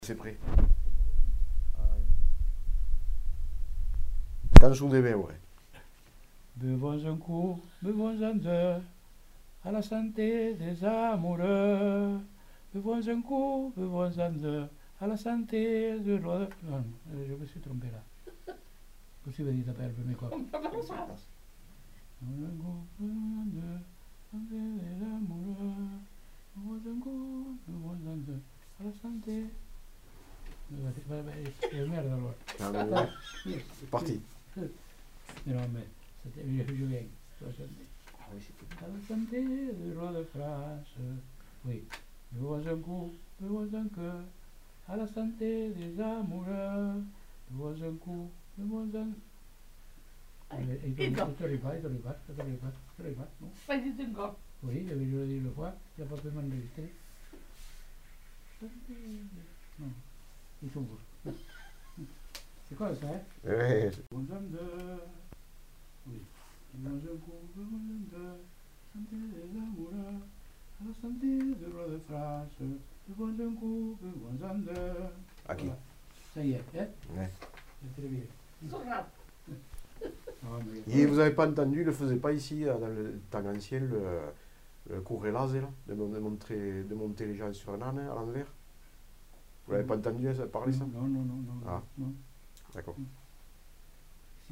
Aire culturelle : Lauragais
Genre : chant
Effectif : 1
Type de voix : voix d'homme
Production du son : chanté
Notes consultables : L'informateur tente de se remémorer la chanson.